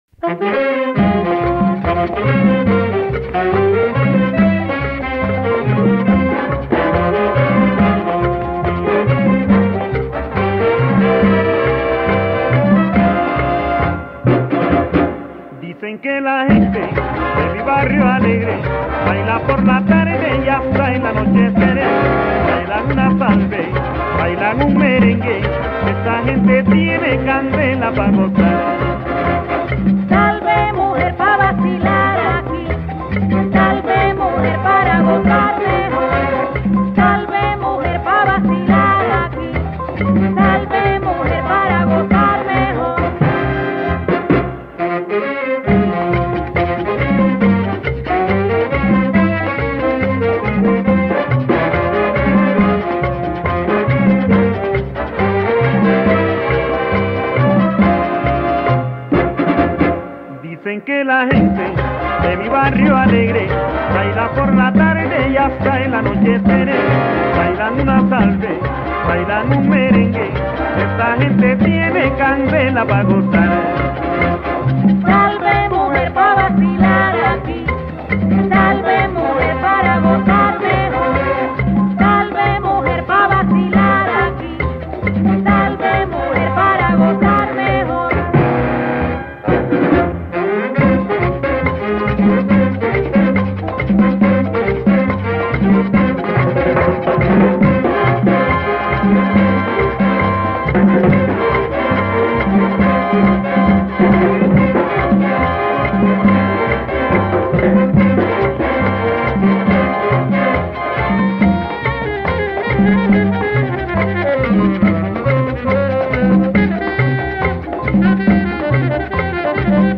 Жанр: Latin
Стиль: Cumbia, Pachanga